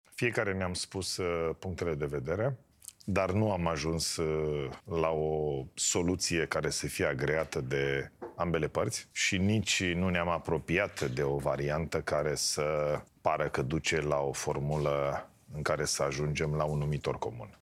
Într-o emisiune la EuroNews România, premierul Ilie Bolojan a afirmat că nu s-a ajuns la niciun acord în urma discuţiilor pe tema reformei pensiilor magistraţilor, care s-au desfăşurat miercuri la Palatul Cotroceni.